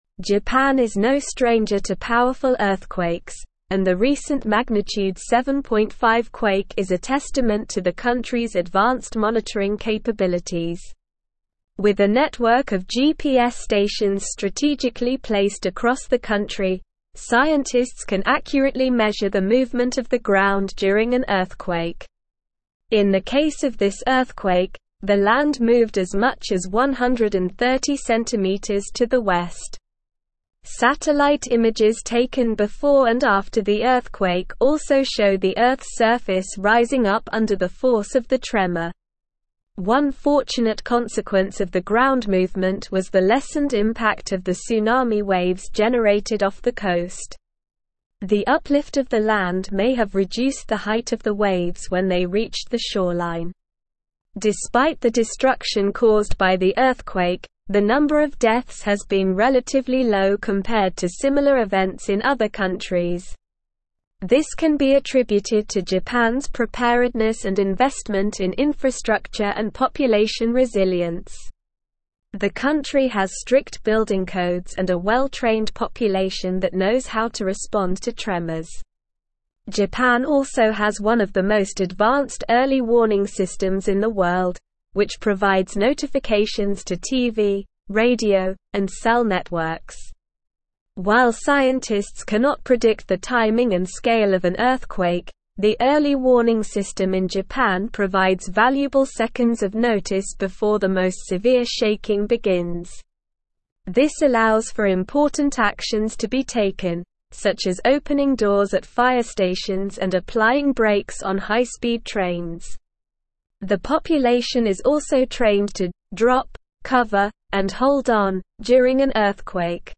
Slow
English-Newsroom-Advanced-SLOW-Reading-Japans-Earthquake-Preparedness-Low-Death-Toll-High-Resilience.mp3